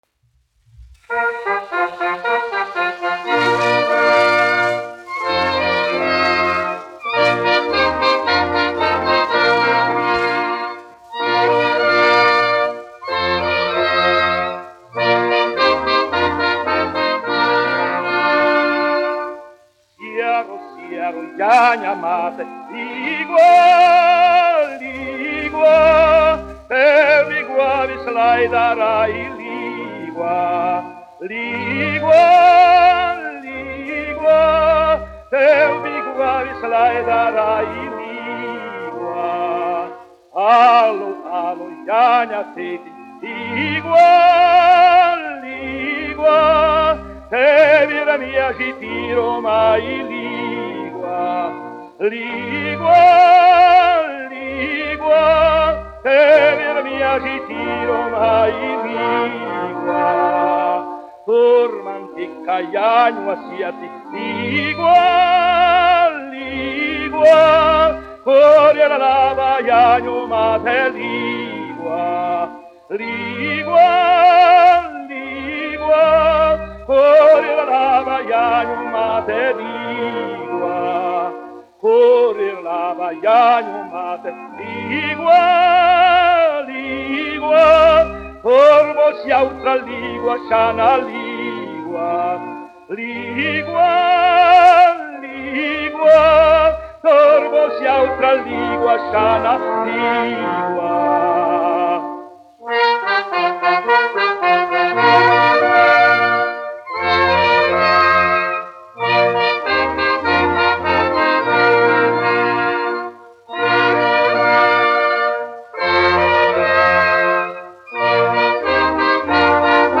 Bērziņš, Rūdolfs, 1881-1949, dziedātājs
1 skpl. : analogs, 78 apgr/min, mono ; 25 cm
Latviešu tautasdziesmas
Latvijas vēsturiskie šellaka skaņuplašu ieraksti (Kolekcija)